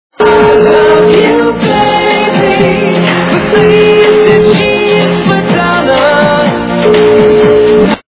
западная эстрада
качество понижено и присутствуют гудки